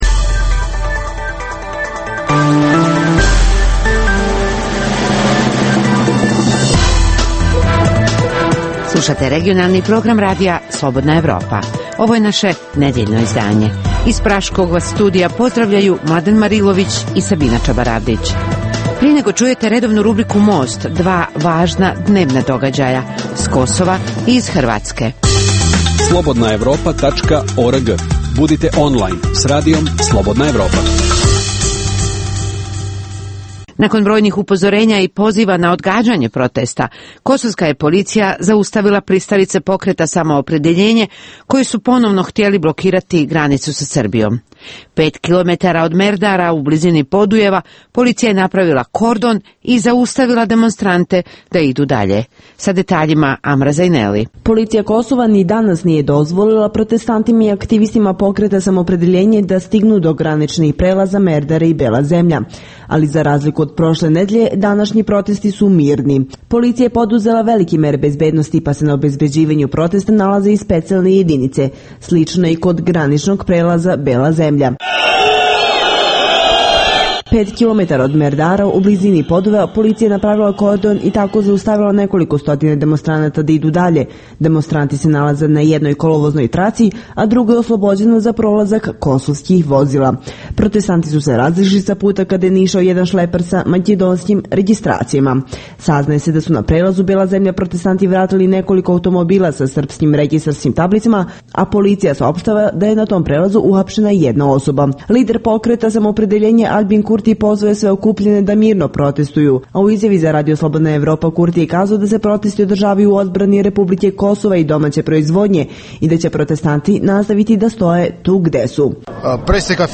u kojem ugledni sagovornici iz regiona diskutuju o aktuelnim temama.